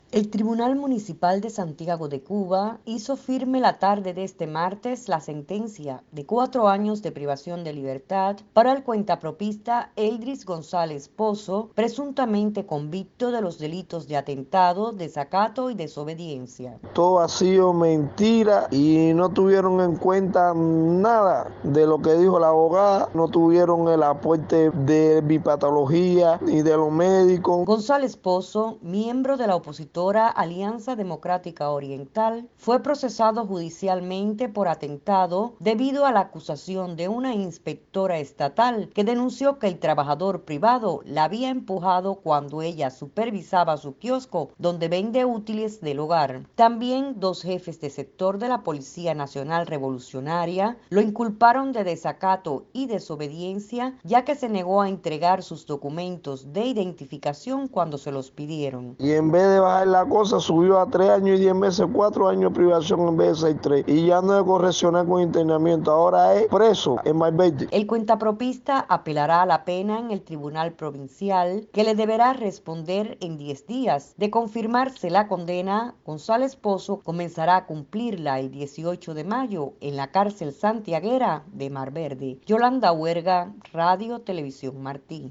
El reportaje